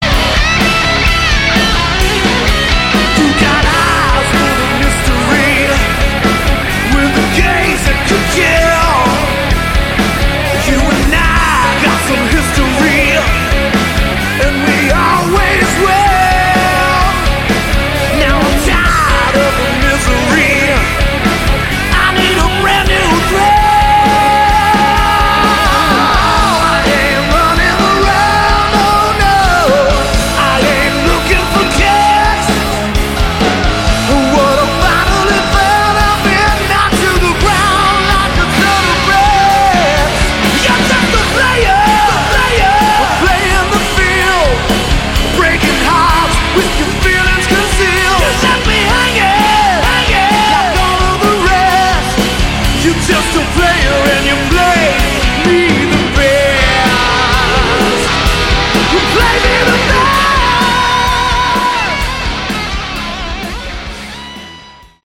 all guitars
lead vocals
bass
keyboards
drums